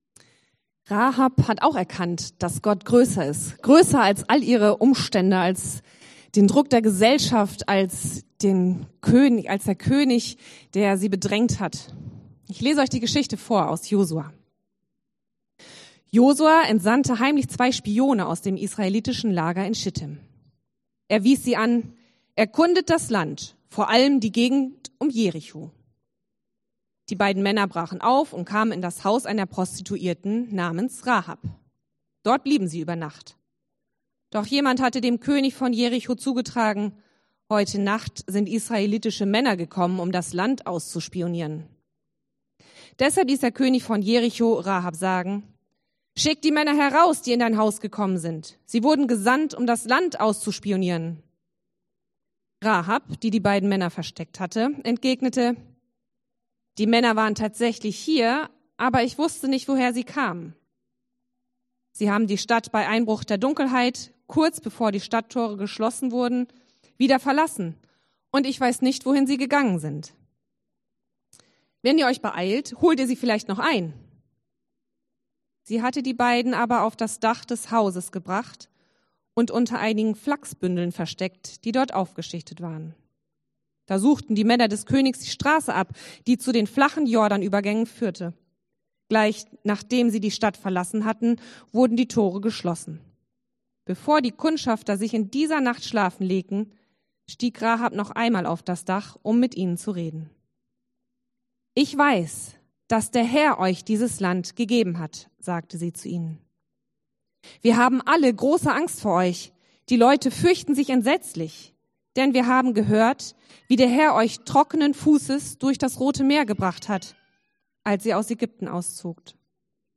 Passage: Josua 2,1-21; 6,15-25 Dienstart: Predigt